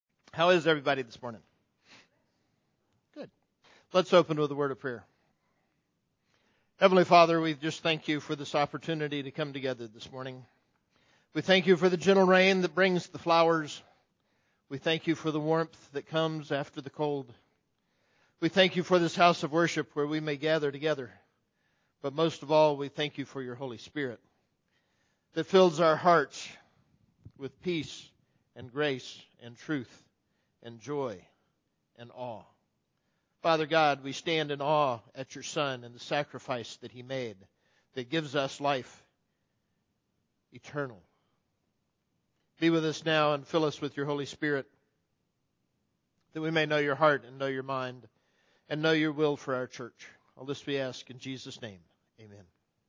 Invocation